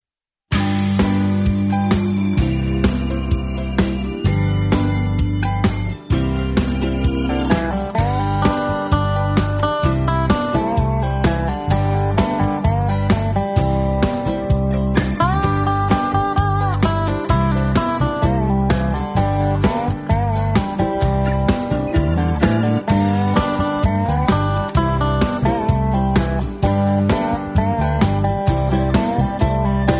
Patter (two instrumentals)